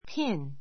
pin pín ピ ン 名詞 ❶ ピン, 留め針 a safety [tie] pin a safety [tie] pin 安全[ネクタイ]ピン a drawing pin a drawing pin 英 画びょう （ 米 thumbtack） ❷ （ピンで留める） ブローチ, バッジ; （ボウリングの） ピン Nancy is wearing a pretty pin.